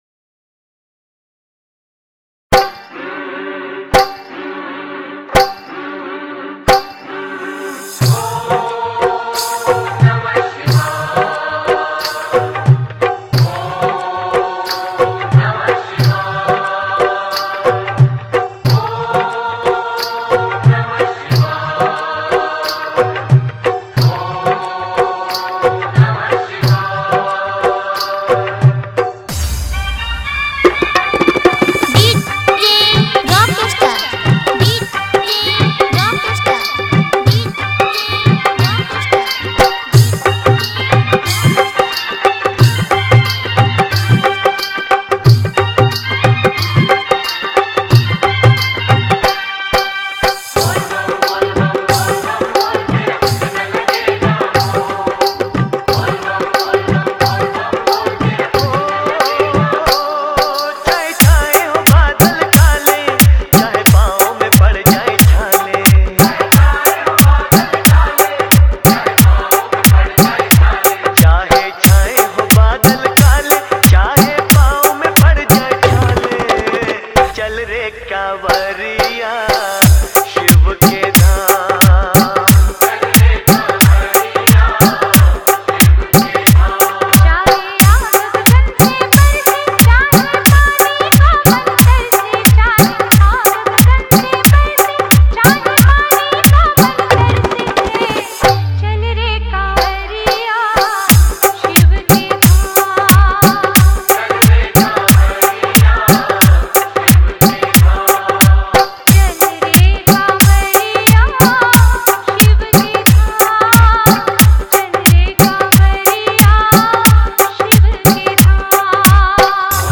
Category:  Bol Bam 2023 Dj Remix Songs